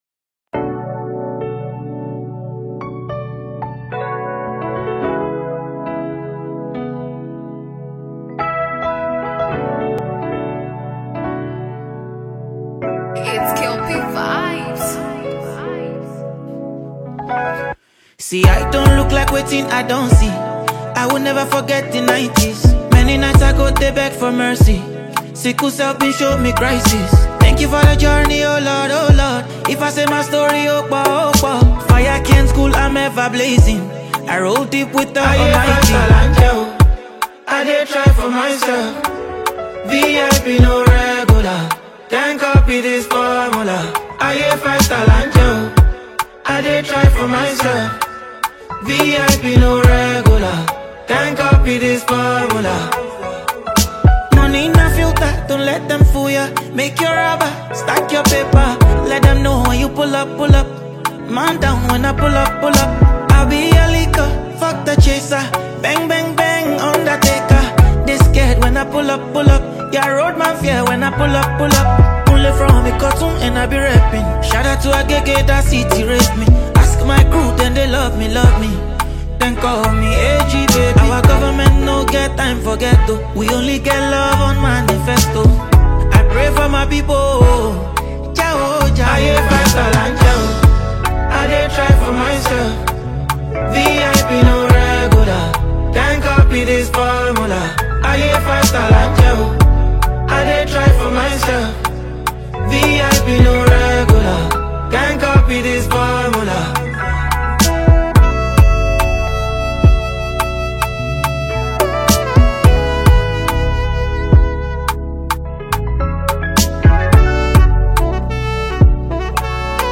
is a Nigerian highlife singer and songwriter.